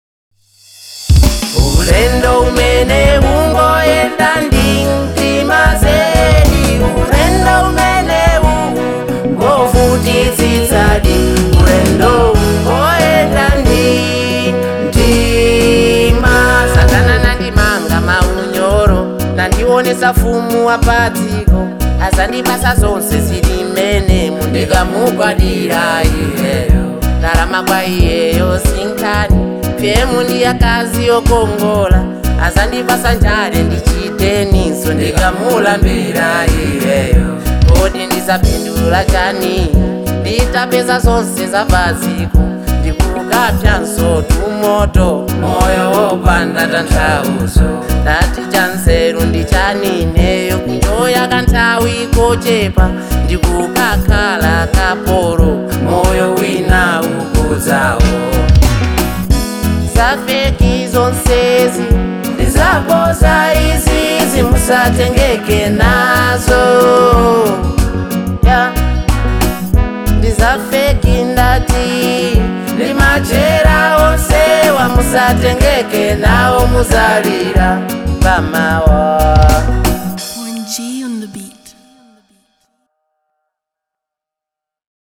Malawian Afro • 2025-07-18